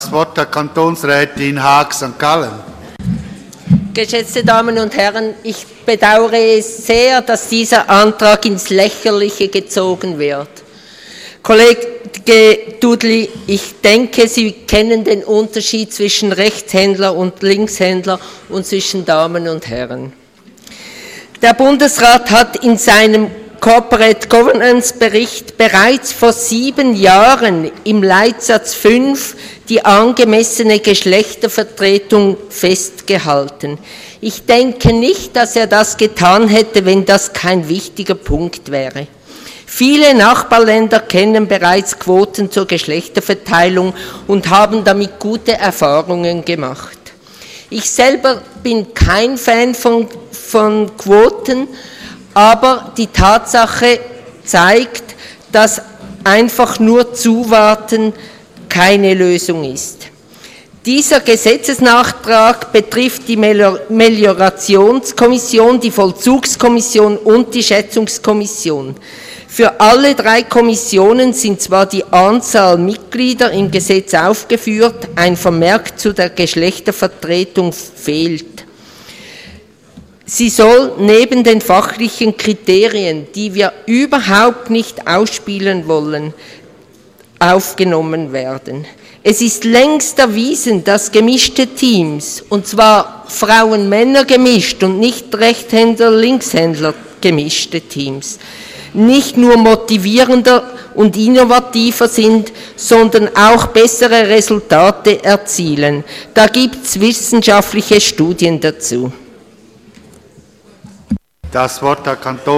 Session des Kantonsrates vom 23. bis 25. Februar 2015